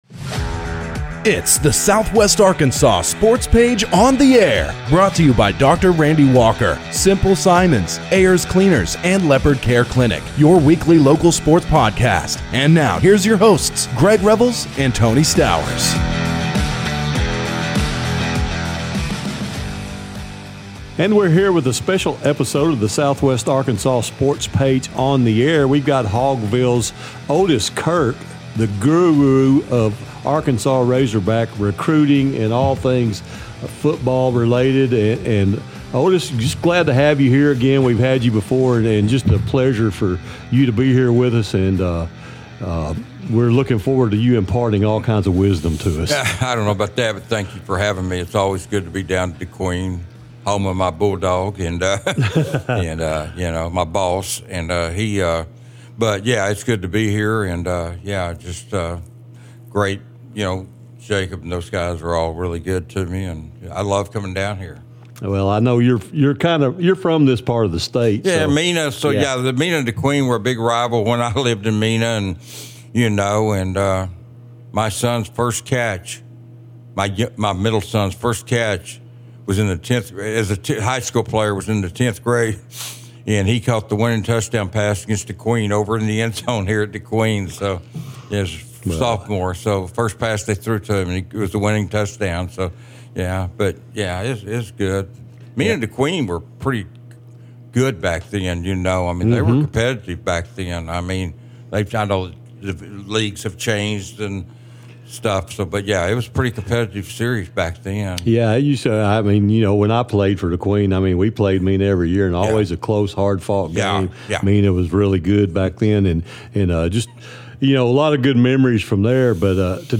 Listen to this excellent conversation on The Razorbacks Season and discussions about NIL and the Portal.